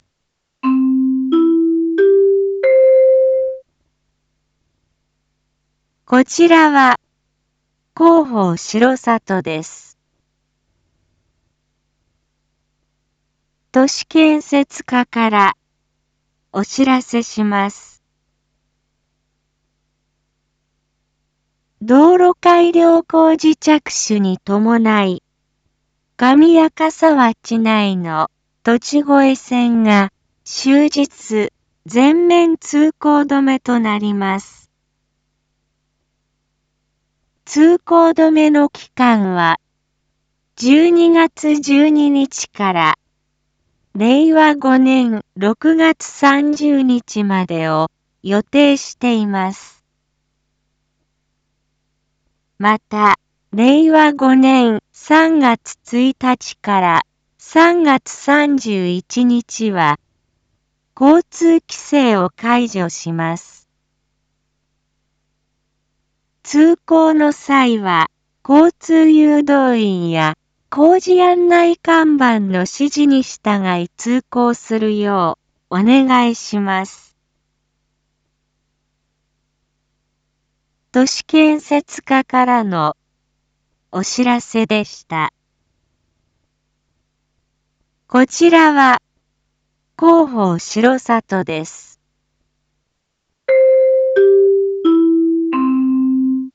Back Home 一般放送情報 音声放送 再生 一般放送情報 登録日時：2022-12-06 07:06:37 タイトル：R4.12.6 7時放送分 インフォメーション：こちらは広報しろさとです。